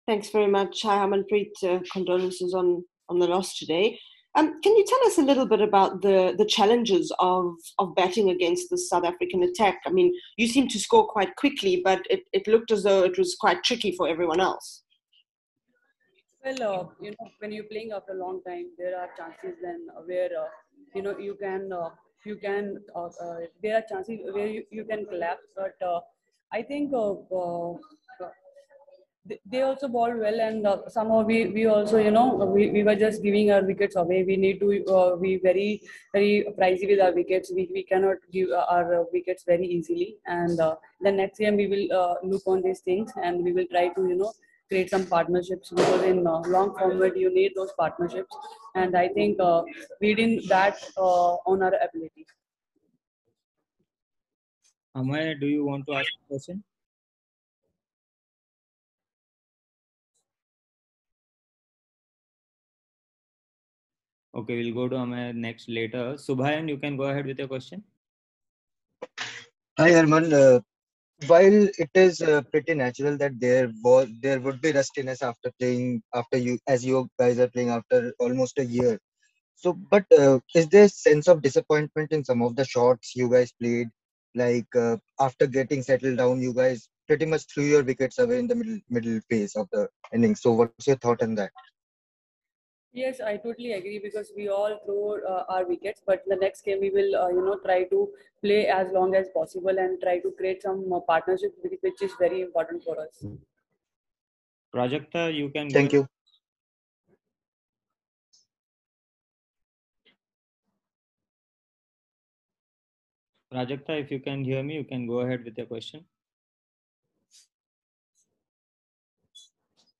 Harmanpreet Kaur addressed a virtual press conference after the 1st ODI against South Africa
Harmanpreet Kaur, vice-captain of the Indian Women’s ODI Team addressed a virtual press conference after the 1st ODI against South Africa at Lucknow.